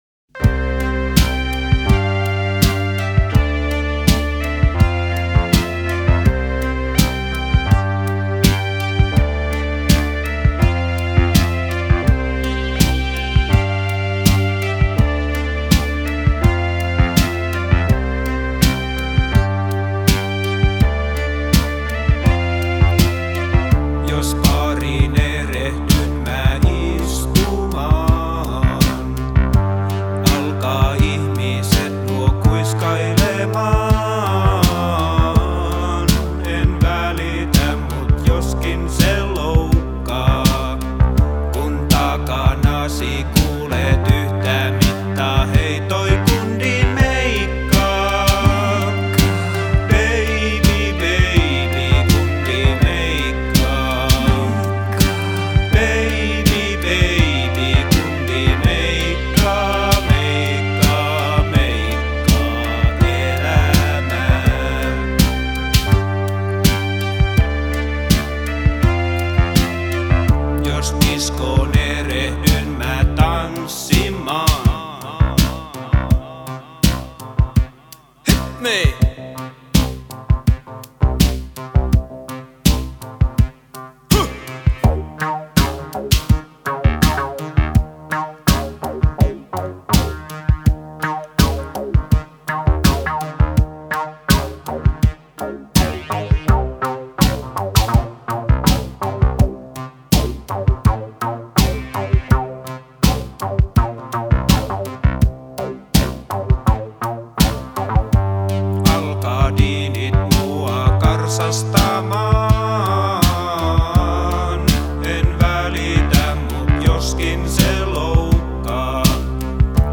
une toute craquante chanson en finlandois